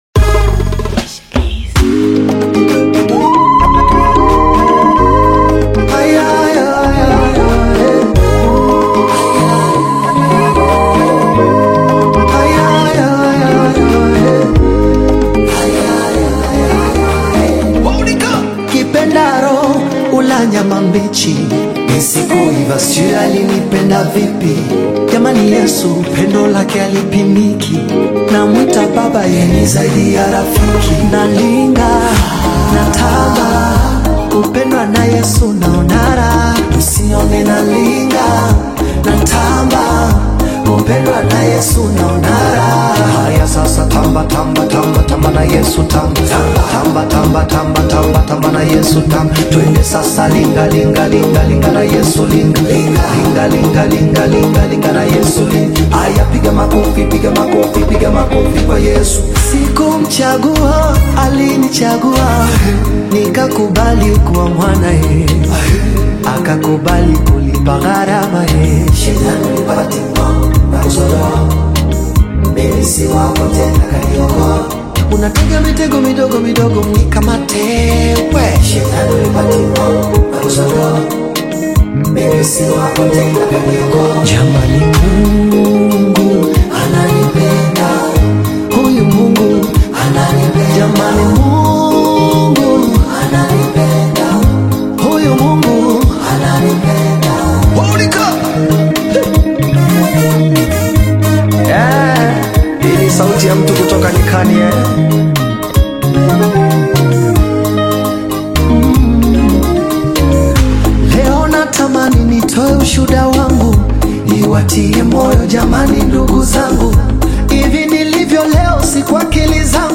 soulful Tanzanian gospel single
emotive vocal delivery and uplifting gospel melodies
Gospel